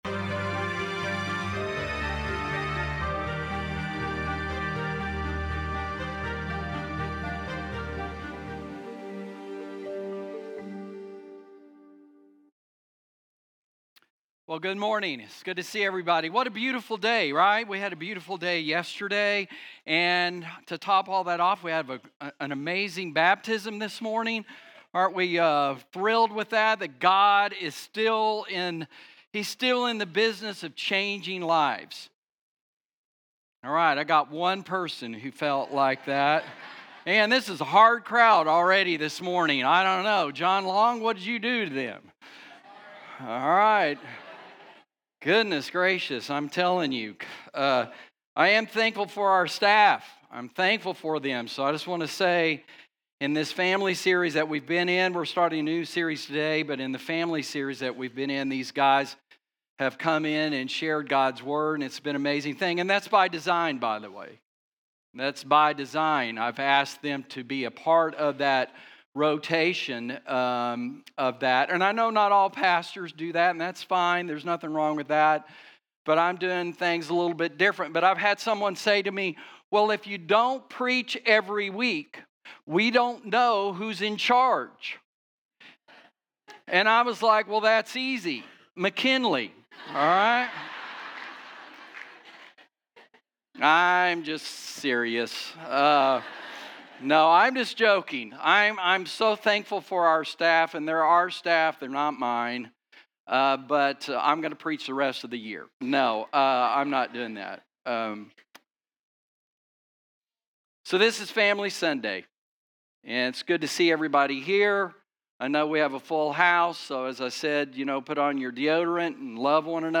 Play Rate Listened List Bookmark Get this podcast via API From The Podcast Weekly sermons from Chisholm Summit Community Church in Burleson, Tx.